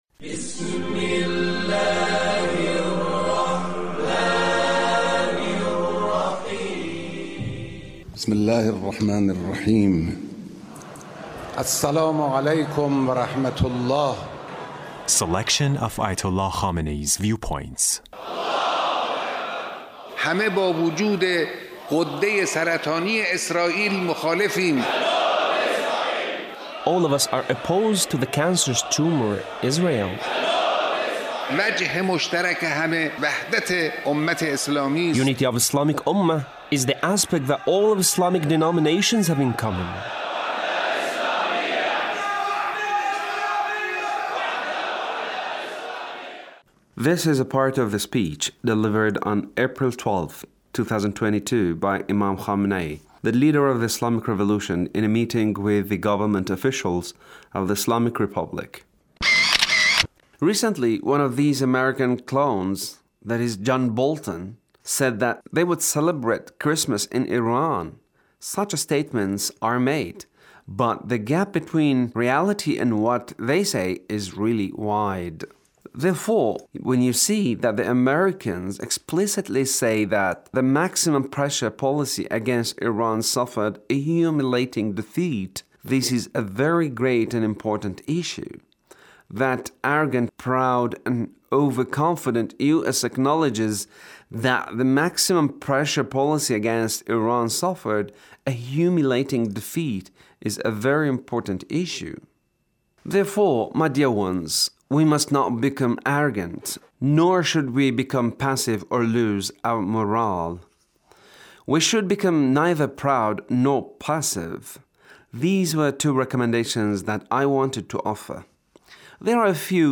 The Leader's speech in a meeting with Government Officials